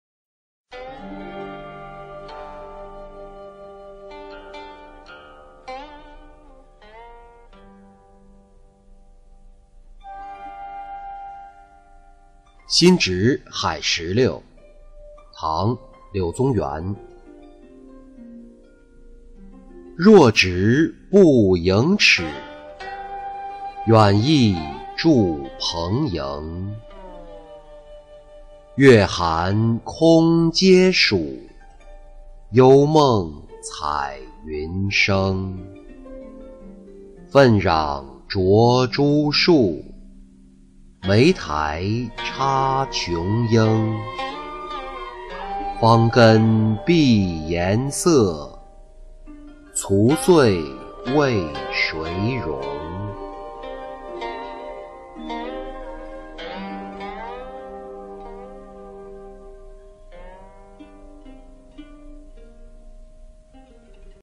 新植海石榴-音频朗读